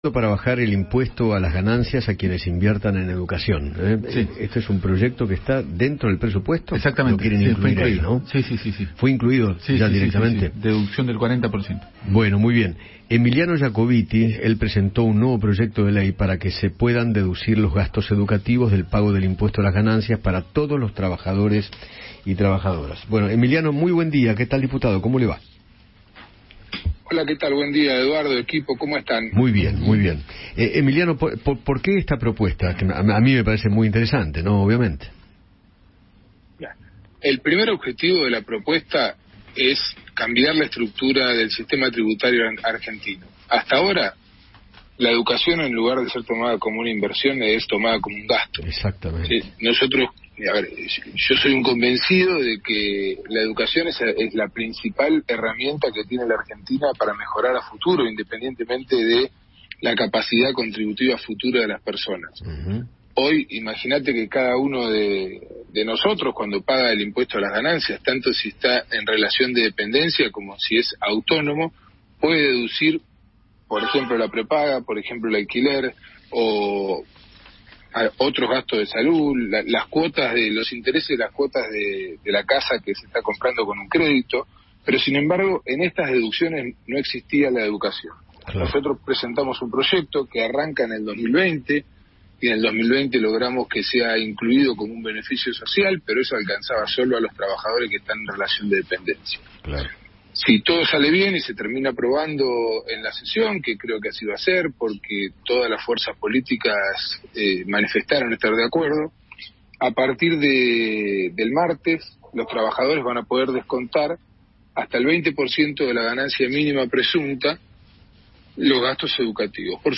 Emiliano Yacobitti, diputado nacional, habló con Eduardo Feinmann sobre el proyecto de ley para deducir los gastos en educación del impuesto a las ganancias.